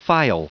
Prononciation du mot phial en anglais (fichier audio)
Prononciation du mot : phial